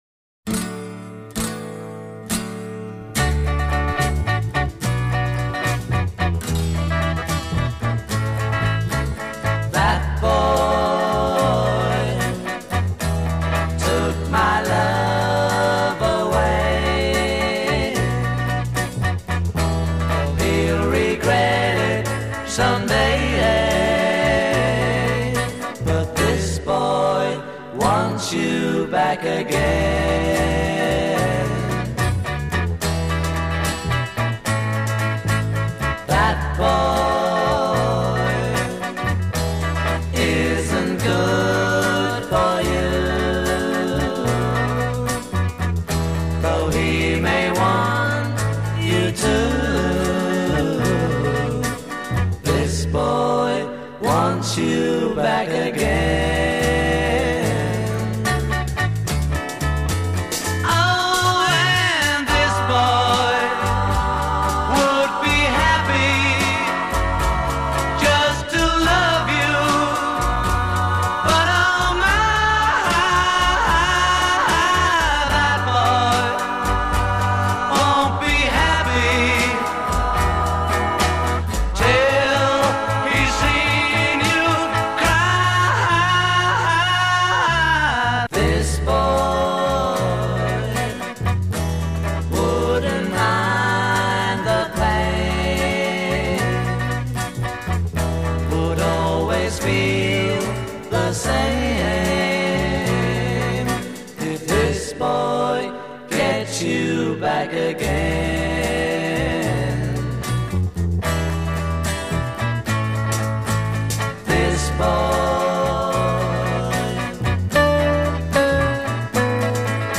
A verse 0: 16 heavy reverberation on choral vocals a
coda : 8+ repeat and fade e